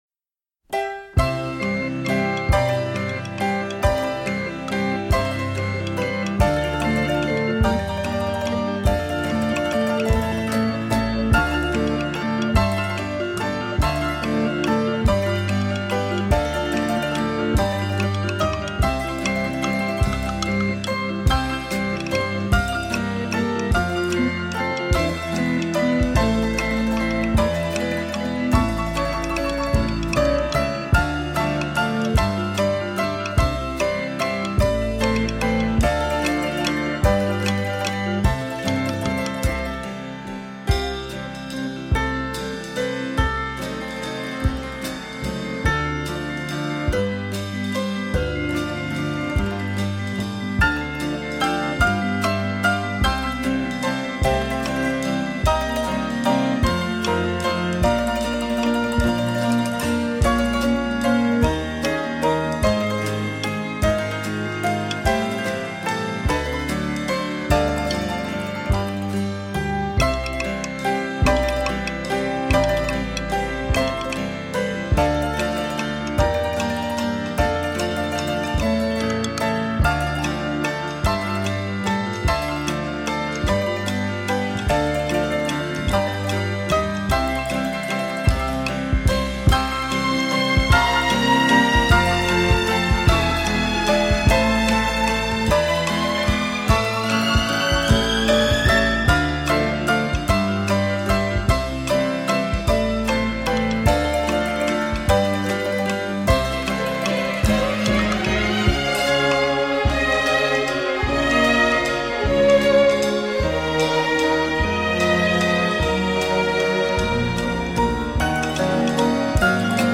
Deux partitions hautement recommandables.
Ironie, tristesse